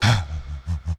Index of /90_sSampleCDs/Zero-G - Total Drum Bass/Instruments - 3/track61 (Vox EFX)
03-Huh Huh.wav